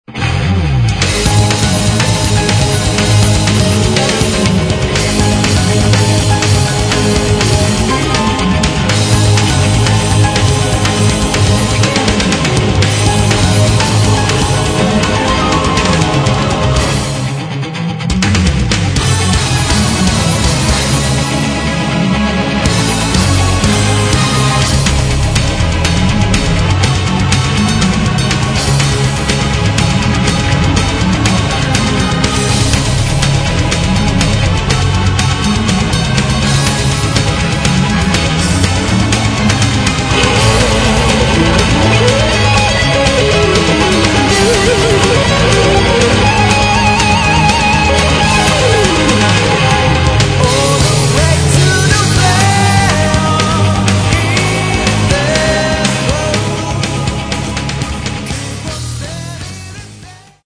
Metal
гитара, все инструменты, вокал